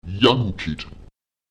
Lautsprecher yanuket [ÈjaNuket] das Hirn, das Gehirn (das Denkorgan)